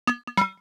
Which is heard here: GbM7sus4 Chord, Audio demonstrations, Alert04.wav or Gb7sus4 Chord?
Alert04.wav